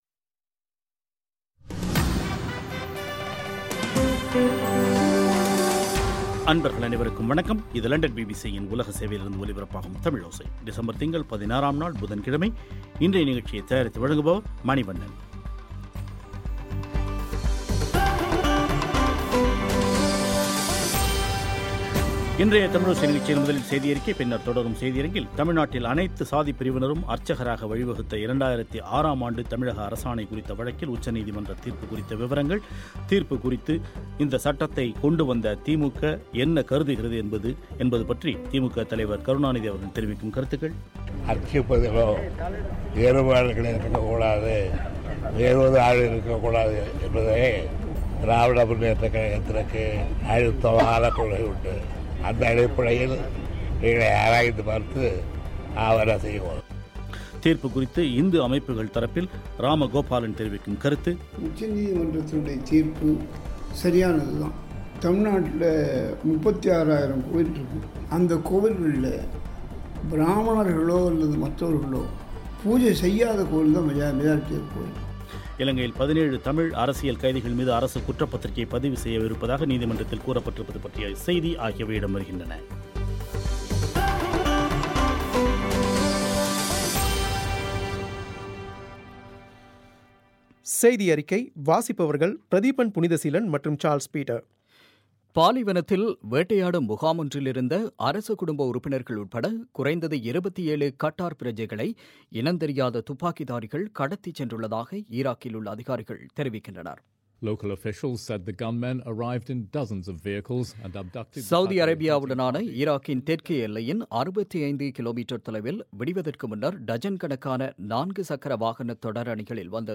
இந்த சட்டத்தைக் கொண்டு வந்த திமுக இந்த தீர்ப்பு குறித்து என்ன கருதுகிறது என்பது பற்றி முன்னாள் சட்ட அமைச்சர் துரை முருகன் பேட்டி